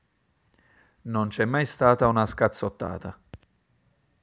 Italian_Speech_Data_by_Mobile_Phone_Reading